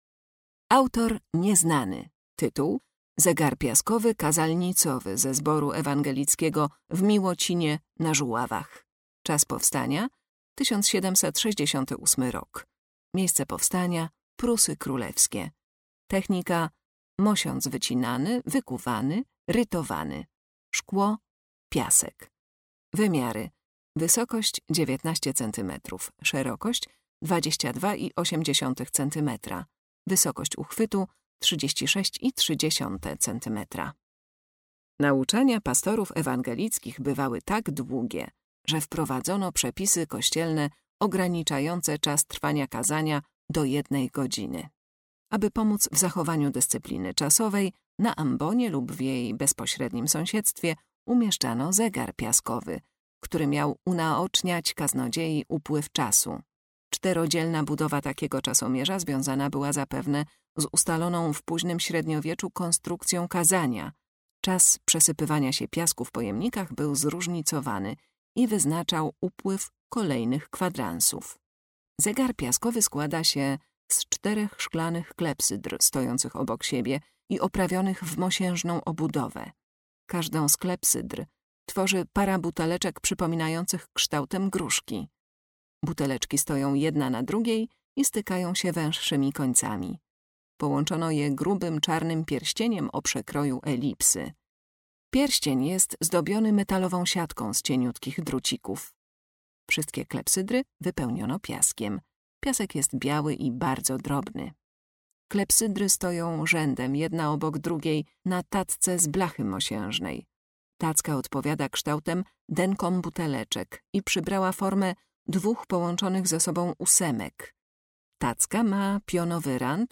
Audiodeskrypcje do wystawy stałej w Oddziale Sztuki Dawnej